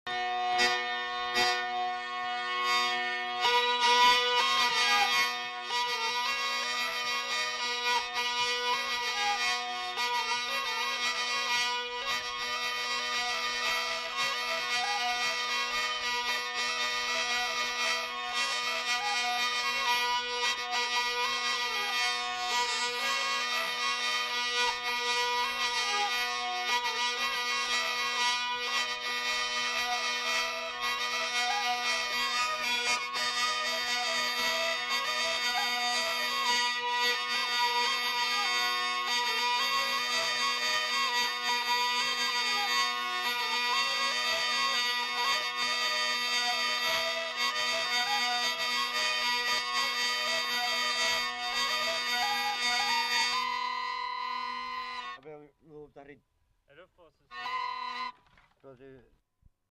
Aire culturelle : Gabardan
Genre : morceau instrumental
Instrument de musique : vielle à roue
Danse : rondeau